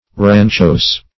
Rancho \Ran"cho\ (r[a^]n"ch[-o]), n.; pl. Ranchos
(r[a^]n"ch[=o]z). [Sp., properly, a mess, mess room. Cf. 2d